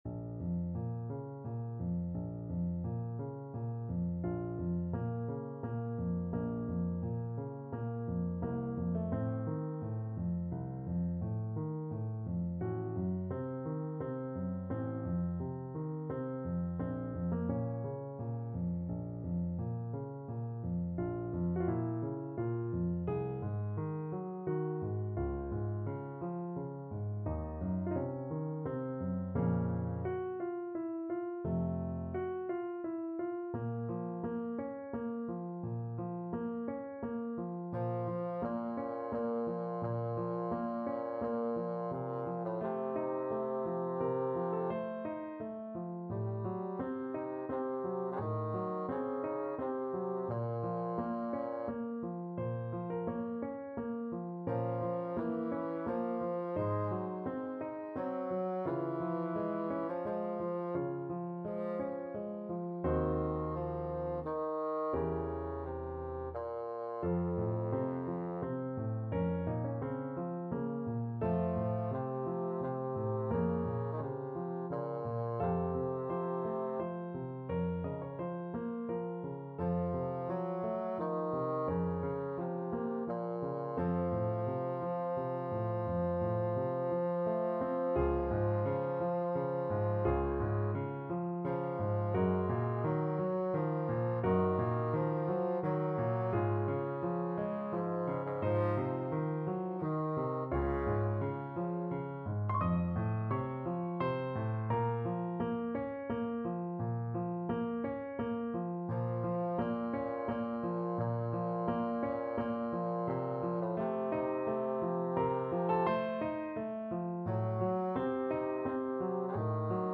Free Sheet music for Bassoon
Bassoon
6/8 (View more 6/8 Music)
Bb minor (Sounding Pitch) (View more Bb minor Music for Bassoon )
Larghetto = c. 86
Classical (View more Classical Bassoon Music)